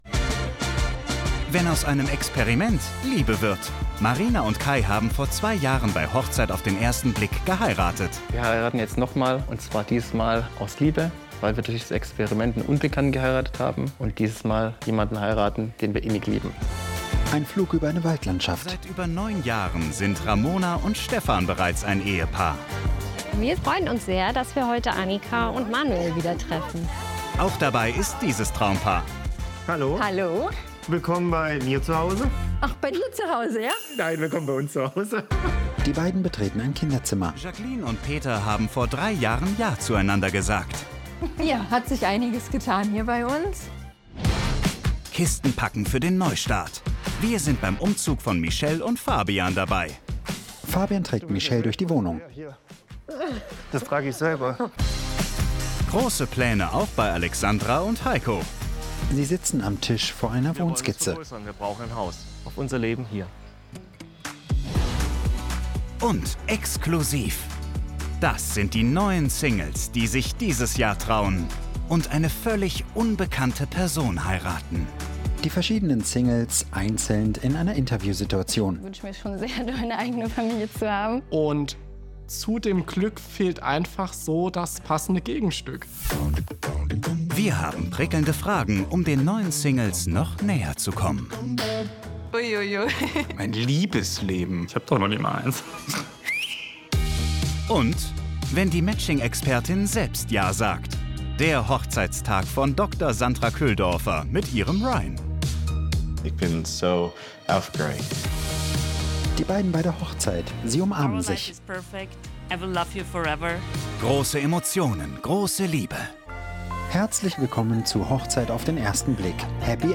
Audiodeskription beschreibt für blinde und sehbehinderte Menschen, was auf dem Bildschirm zu sehen ist. In Dialogpausen erklärt ein Sprecher in knappen und präzisen Worten, was nicht über die Tonspur erkenntlich ist.
Auch die Bewegungen und Gesichtsausdrücke der Charaktere werden beschrieben – eben alles, was für die Handlung des Filmes oder der Serie wichtig ist. So entsteht eine Hörfassung, die das ganze Bild wiedergibt – nur auf der Audioebene.